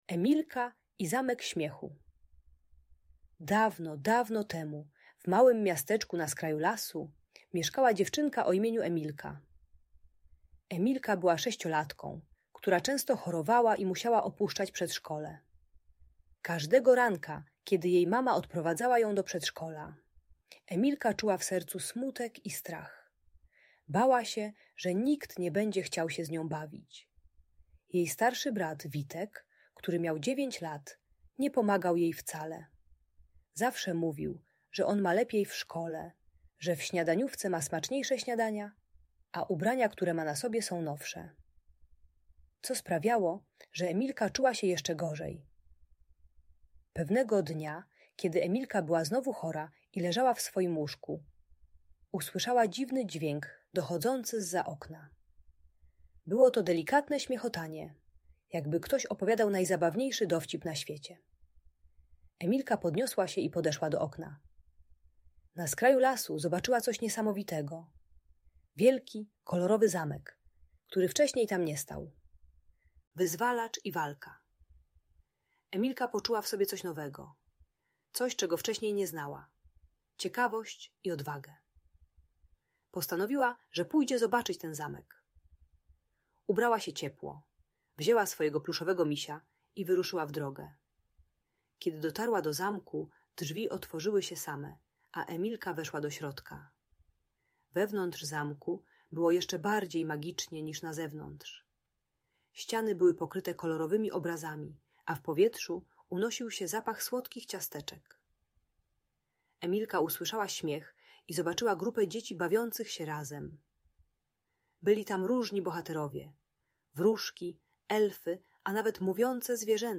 Emilka i Zamek Śmiechu - Audiobajka